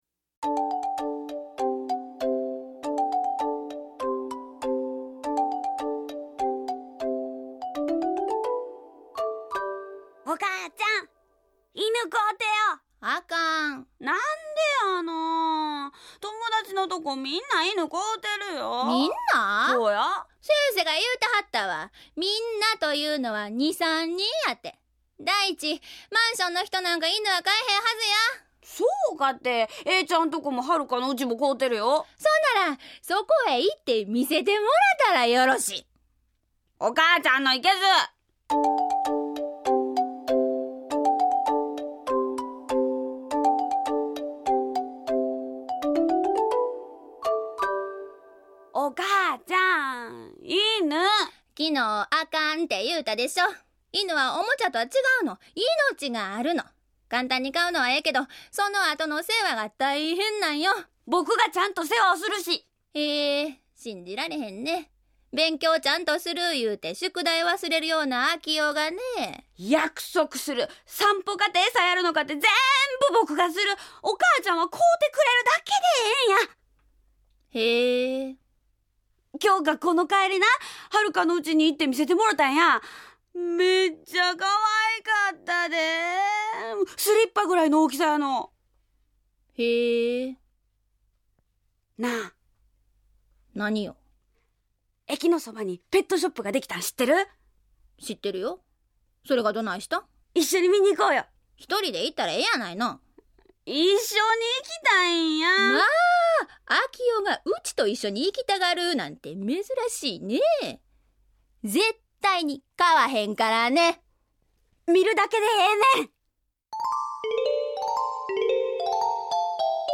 ラジオドラマ「LIFE」第１０回　「別れ」